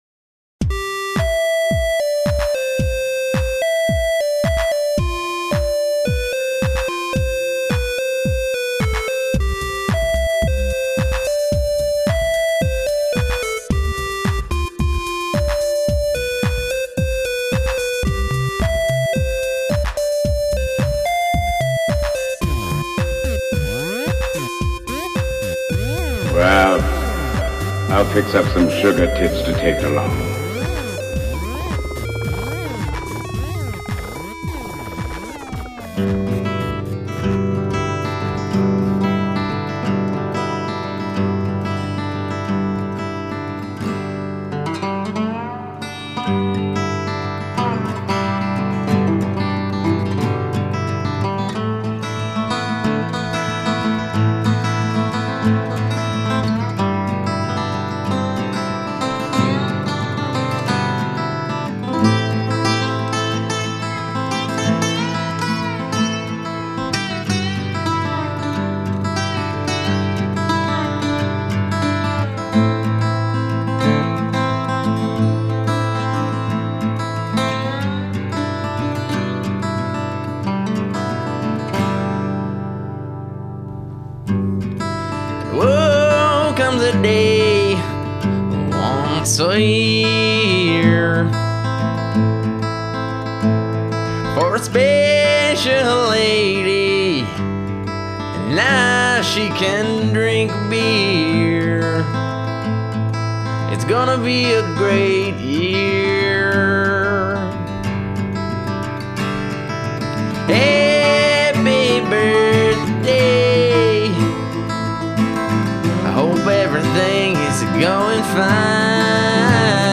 dance/electronic
Eighties/synthpop